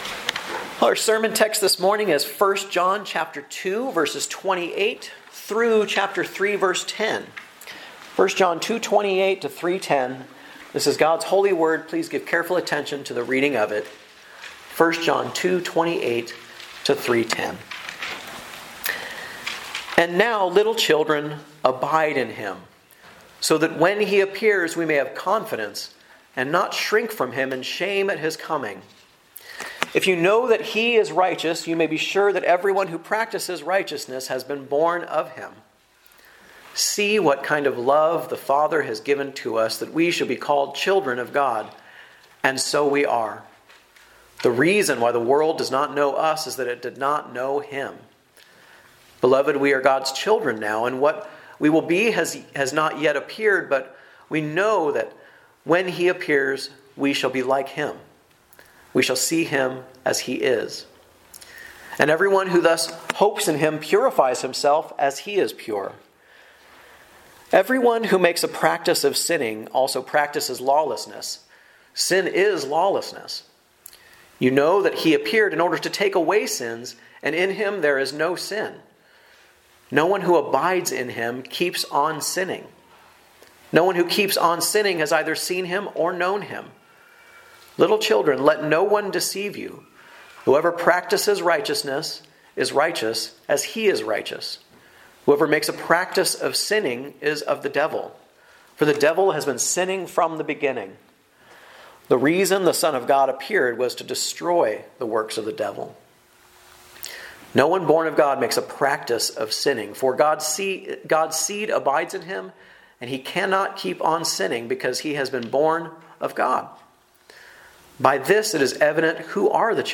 A message from the series "Guest Preacher."